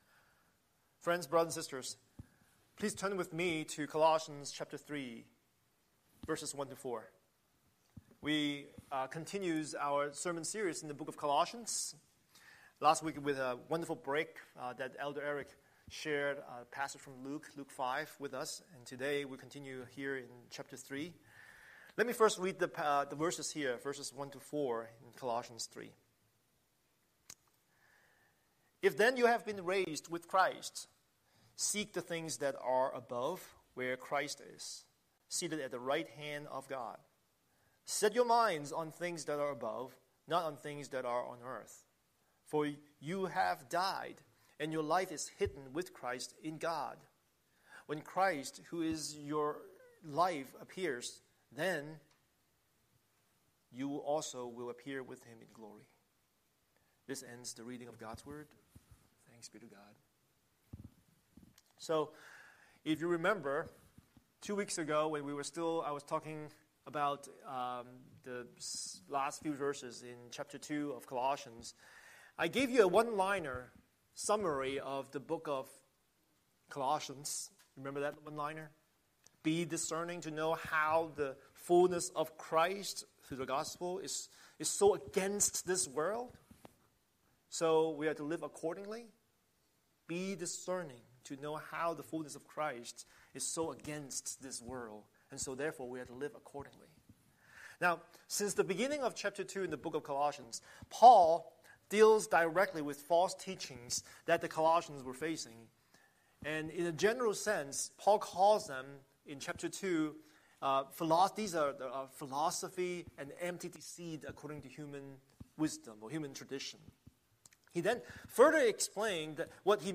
Scripture: Colossians 3:1-4 Series: Sunday Sermon